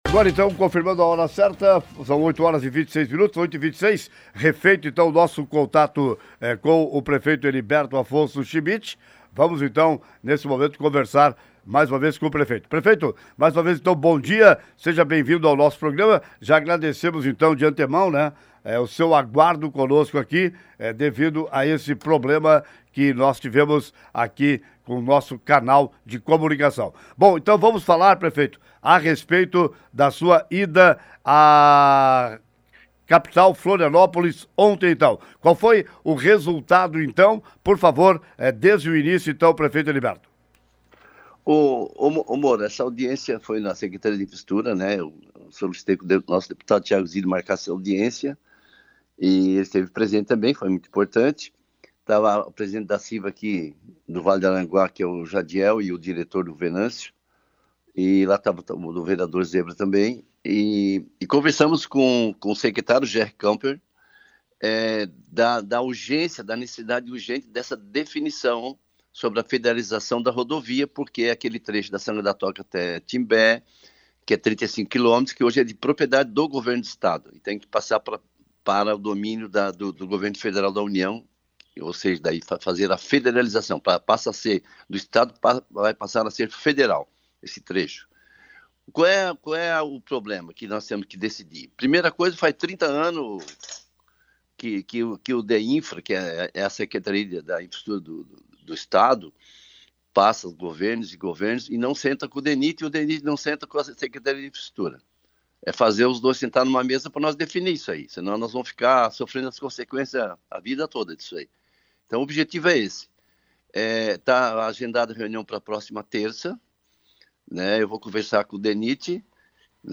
Federalização da SC-285: Nova reunião ficou agendada para a próxima semana: Informação foi trazida pelo Prefeito Heriberto Schmidt nesta manhã no Programa Café com Notícias.
Entrevista-Com-Prefeito-Heriberto.mp3